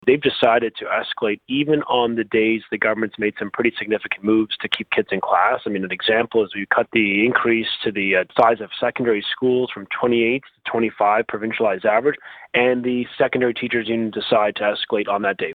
Education Minister Stephen Lecce spoke to Quinte News stating they want to see students in schools, and mediation is on the table with OECTA (Catholic), EFTO (public elementary), OSSTF (public secondary) and AEFO (French) unions.